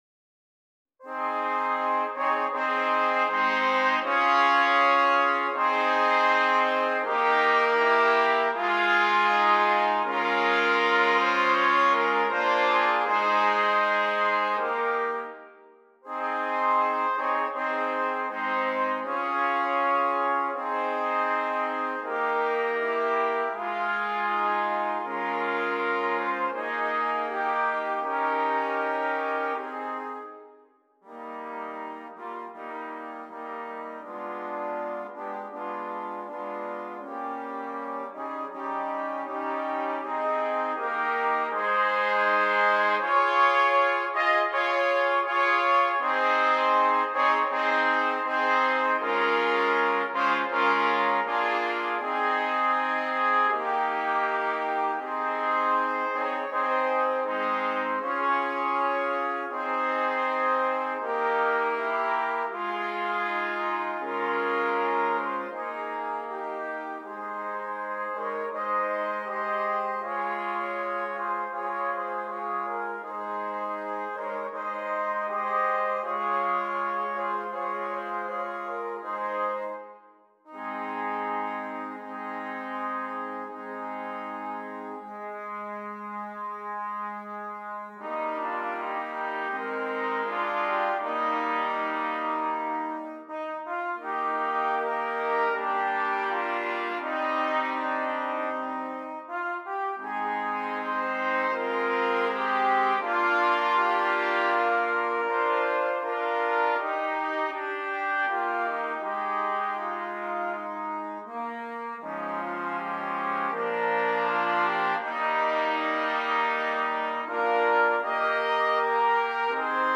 4 Trumpets